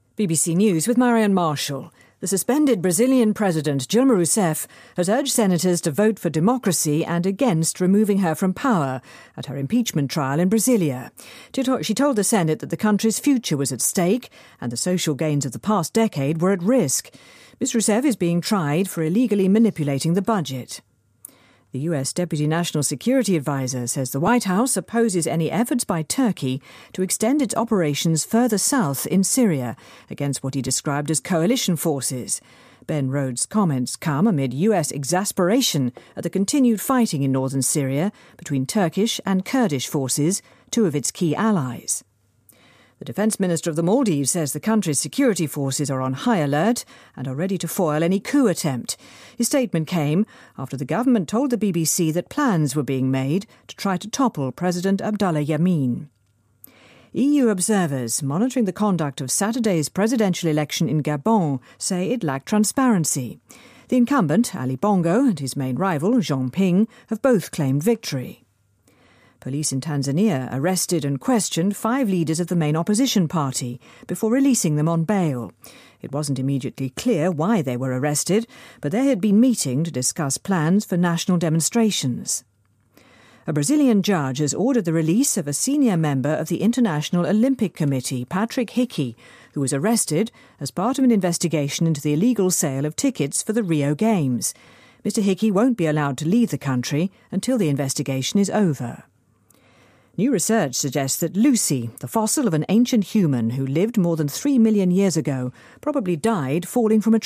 BBC news,巴西警方下令释放奥委会官员
日期:2016-09-01来源:BBC新闻听力 编辑:给力英语BBC频道